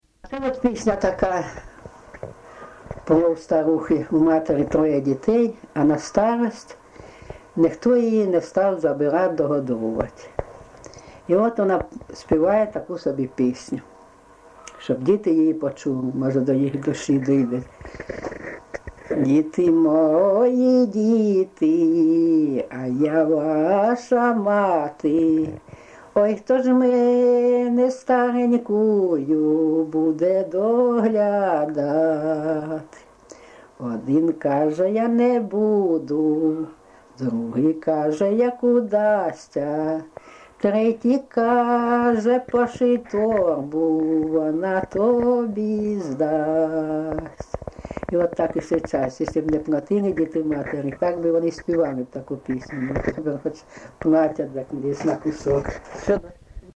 ЖанрПісні з особистого та родинного життя
Місце записус. Курахівка, Покровський район, Донецька обл., Україна, Слобожанщина